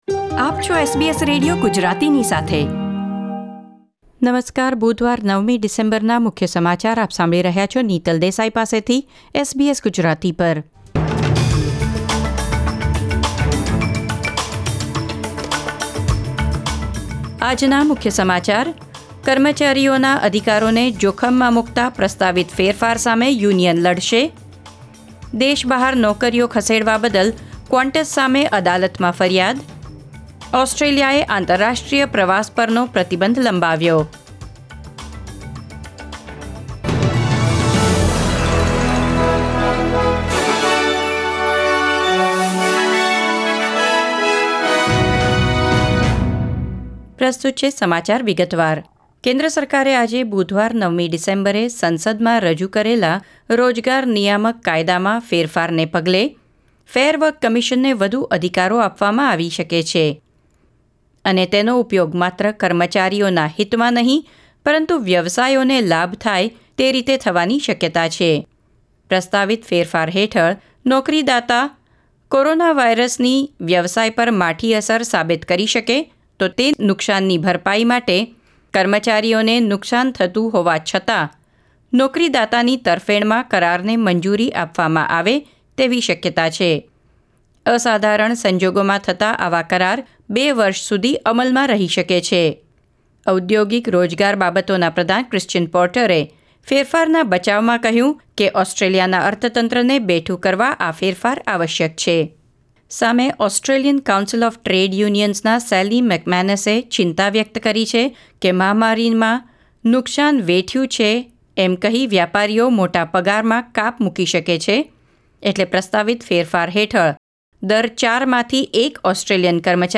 SBS Gujarati News Bulletin 9 December 2020